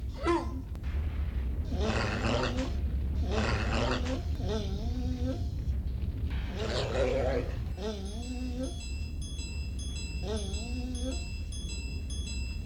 Guardie_Roars.ogg